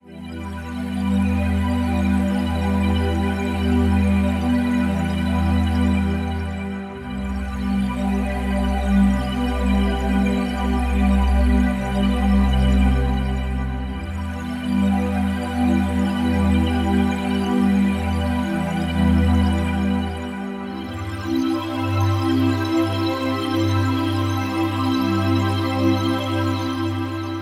悲伤的氛围和弦
标签： 70 bpm Ambient Loops Piano Loops 4.61 MB wav Key : Unknown
声道立体声